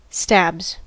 Ääntäminen
Ääntäminen US Haettu sana löytyi näillä lähdekielillä: englanti Käännöksiä ei löytynyt valitulle kohdekielelle. Stabs on sanan stab monikko.